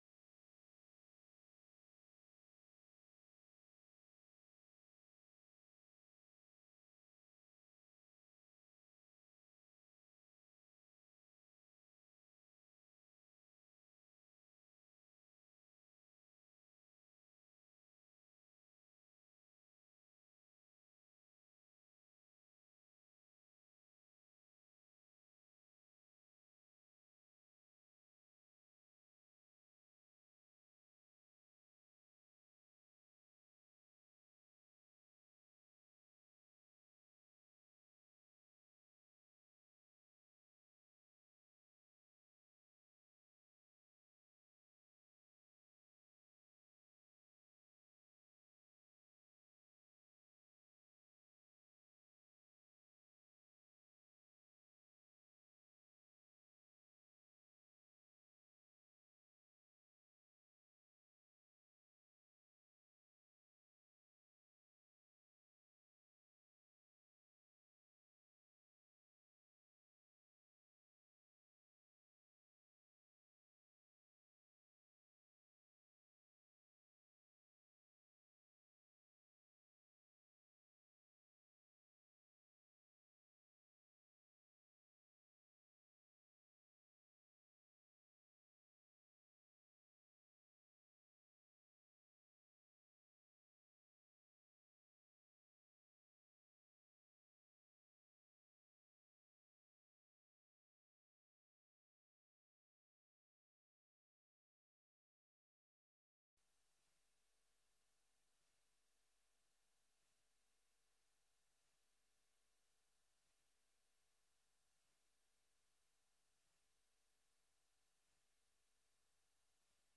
29ª Reunião Ordinária 22 de maio de 2025